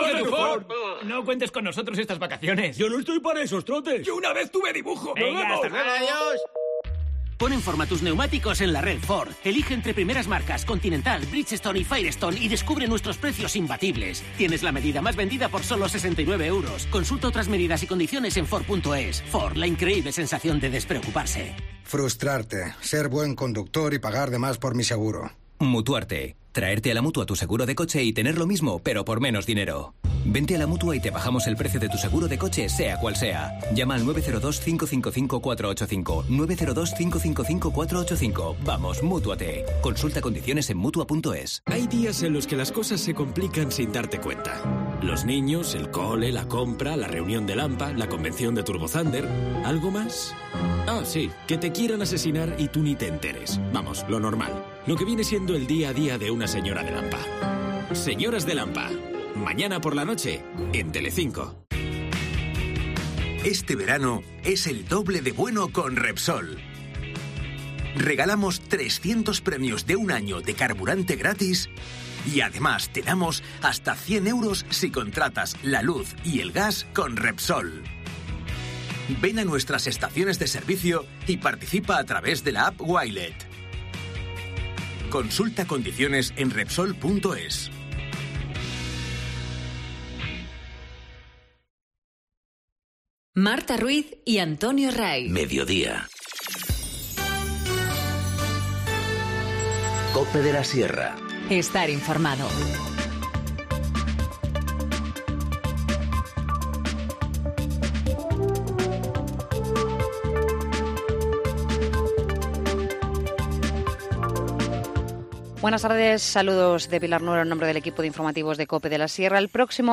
Informativo Mediodía 16 julio 14:20h